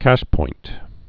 (kăshpoint)